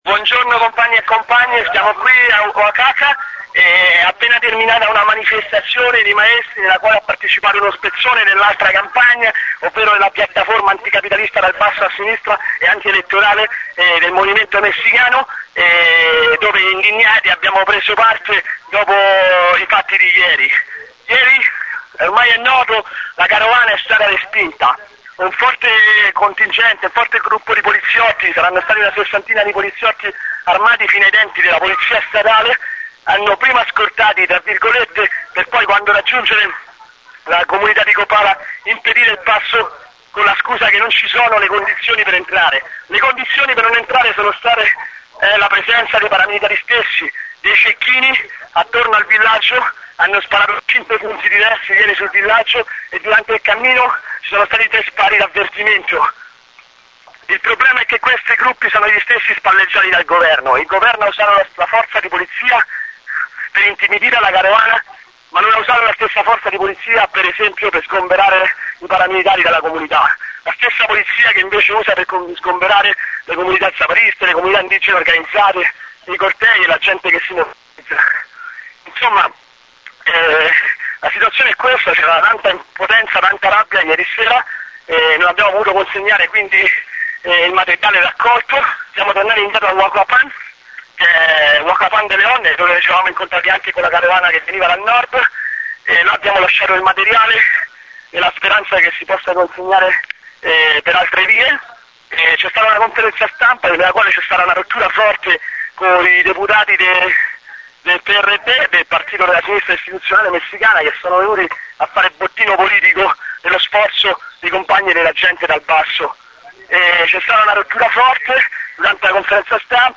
Notizia dellle 18:30.
Reporte carabana Copala Italiano.mp3